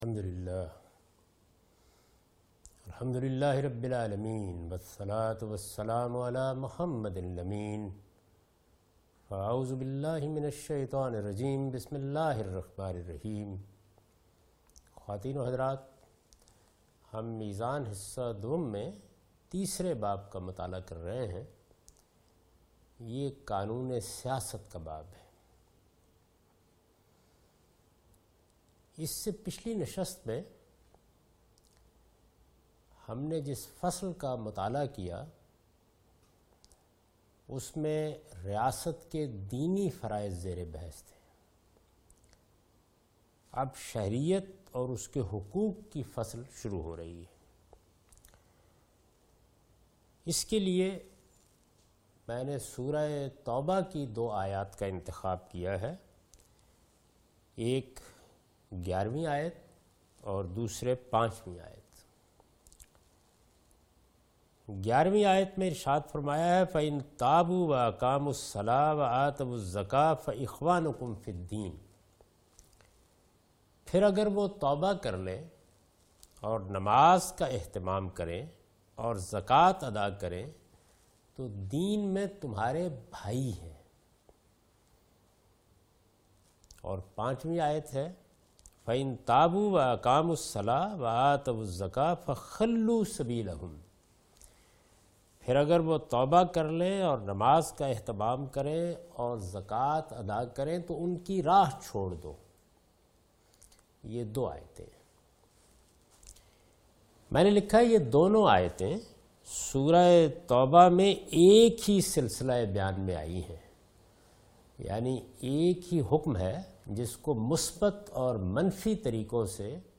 A comprehensive course on Islam, wherein Javed Ahmad Ghamidi teaches his book ‘Meezan’.
In this lecture he teaches the topic 'The Political Shari'ah' from 2nd part of his book. Religious obligations of citizens of an Islamic government are discussed in this sitting.